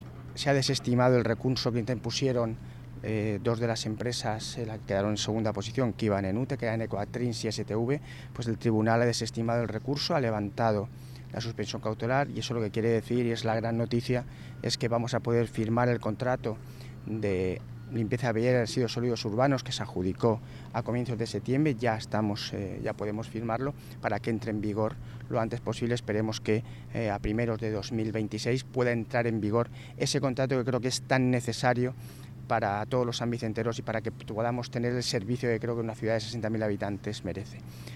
Declaraciones-del-alcalde.mp3